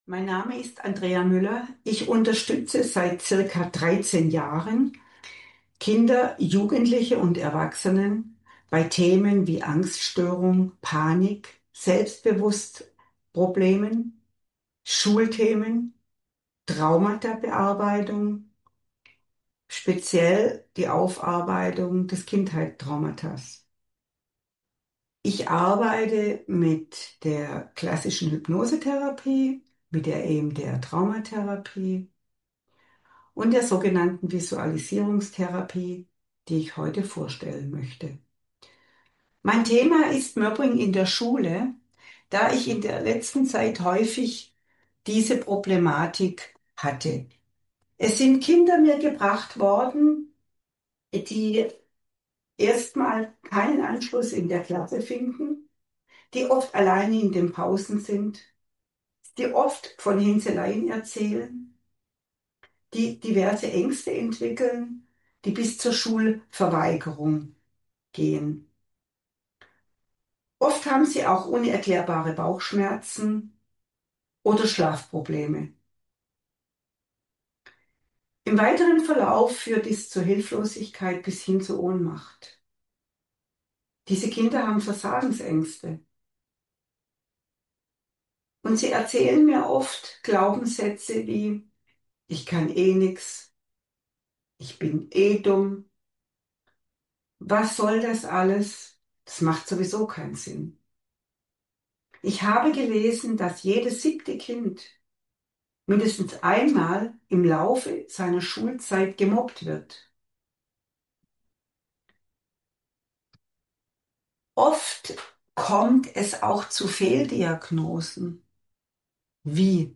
Vortrag zum Thema Mobbing